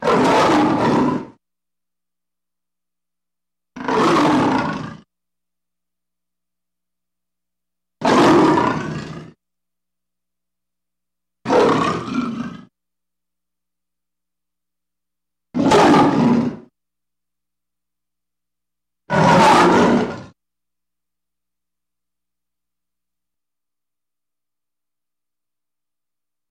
Звуки рычания льва
Серия звуков с рычанием льва нарежьте сами этот звук его можно использовать для монтажа